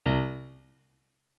MIDI-Synthesizer/Project/Piano/16.ogg at 51c16a17ac42a0203ee77c8c68e83996ce3f6132